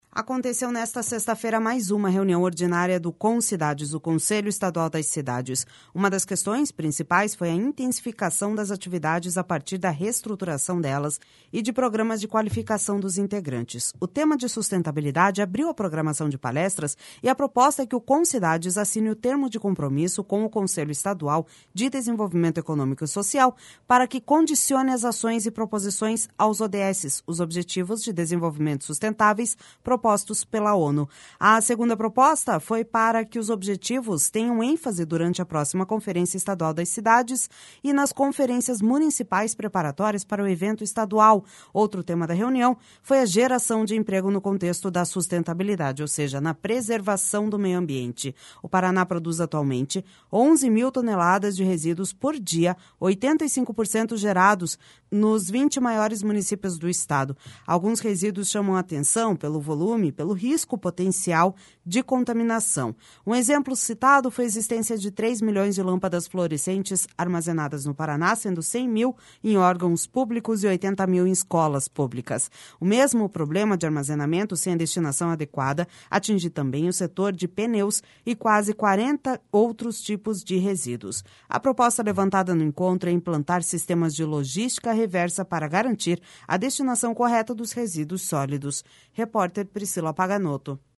A proposta levantada no encontro é implantar sistemas de logística reversa para garantir a destinação correta dos resíduos sólidos. (Repórter